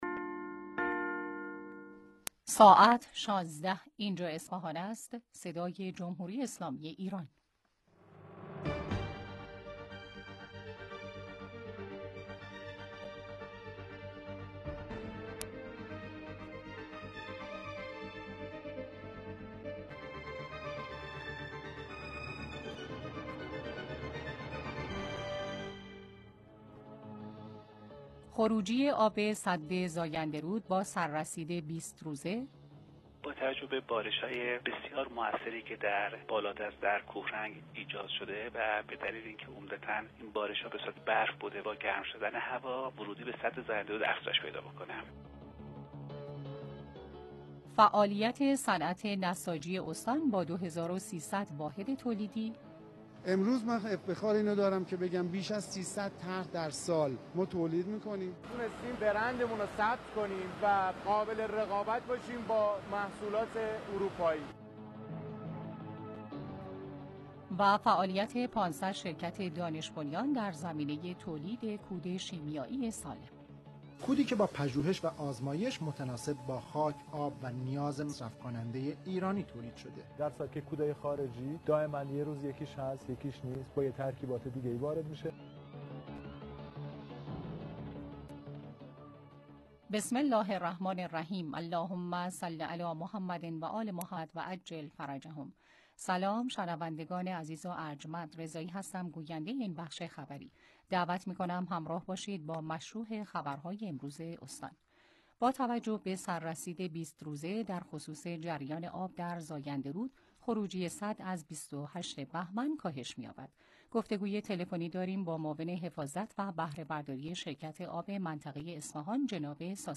انعکاس رویدادهای مهم از بخش خبری 16 رادیو